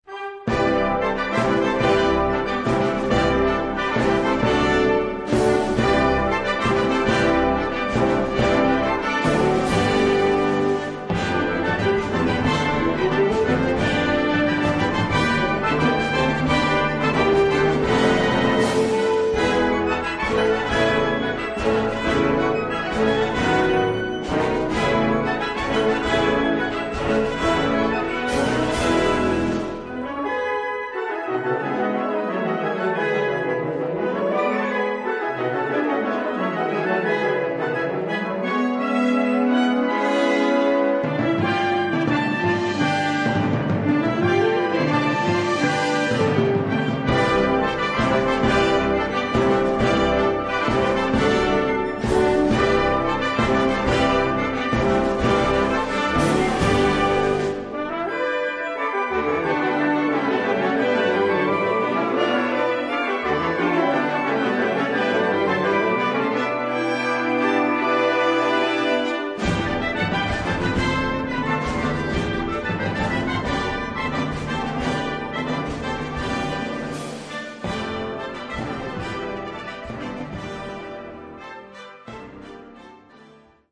Gattung: 8-sätzige Suite
Besetzung: Blasorchester